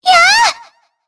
Shea-Vox_Attack3_jp.wav